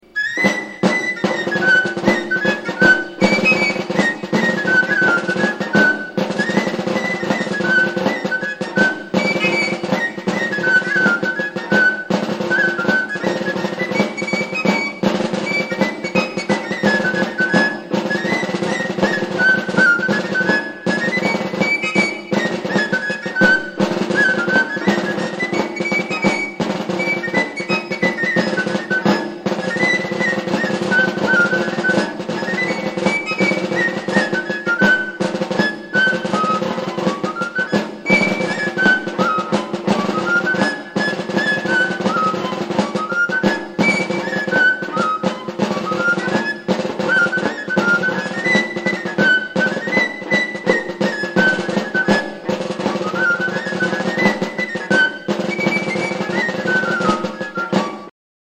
Para hacer este documento audiovisual contactaron con músicos que conocían perfectamente el instrumento.